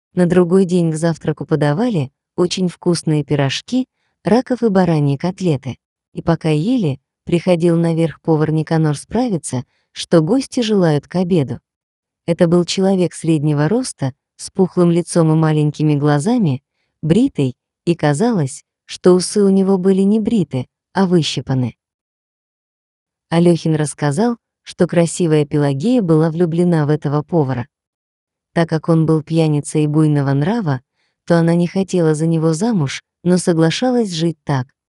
Примеры аудиокниг
Выберите одного из 4-х наших роботизированных дикторов:
Речь робота обладает естественным и выразительным тоном и неизменно высоким качеством